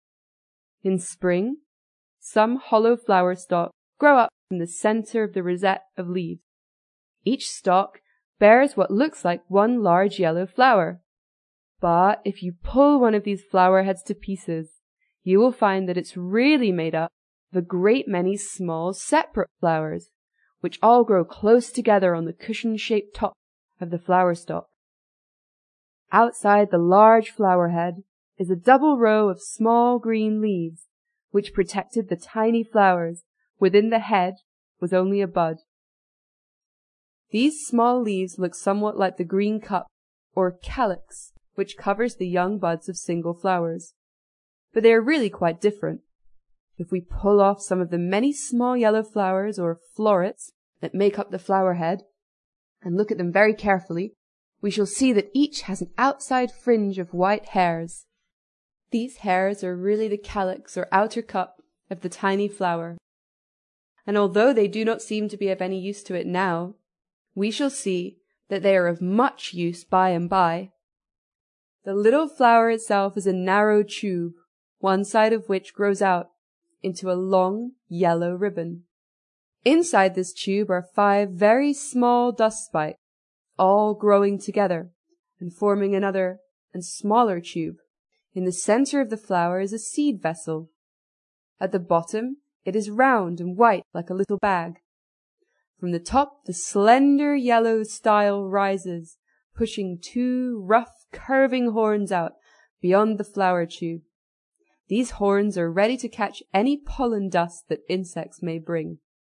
在线英语听力室英国学生科学读本 第74期:蒲公英(2)的听力文件下载,《英国学生科学读本》讲述大自然中的动物、植物等广博的科学知识，犹如一部万物简史。在线英语听力室提供配套英文朗读与双语字幕，帮助读者全面提升英语阅读水平。